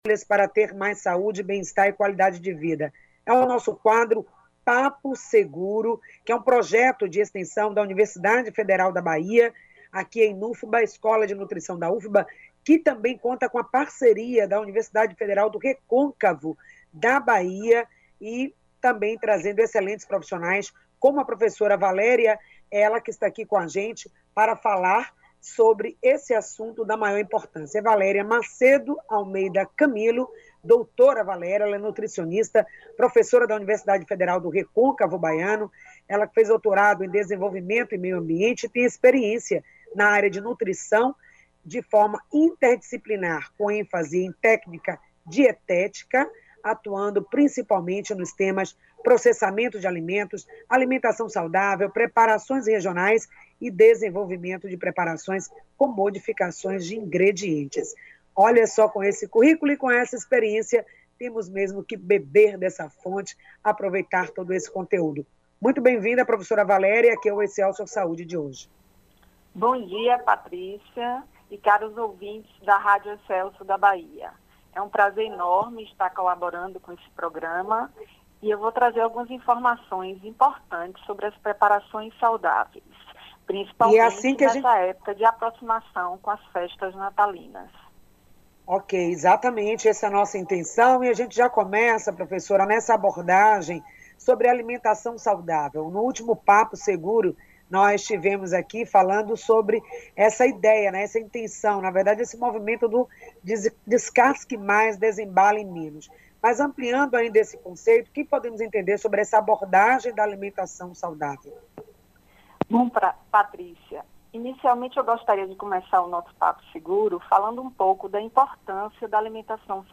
Papo Seguro - Preparos Saudáveis - Entrevista -
Mantendo a parceria de cooperação técnica entre a Escola de Nutrição da Universidade Federal da Bahia, o Programa Excelsior Saúde , apresentou mais uma edição ao vivo do Quadro: Papo Seguro: diálogos sobre alimentação e nutrição em tempos de pandemia.
PAPO-SEGURO-ALIMENTOS-SAUDAVEIS-CEIA-DE-NATAL-ENTREVISTA.mp3